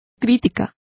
Complete with pronunciation of the translation of censure.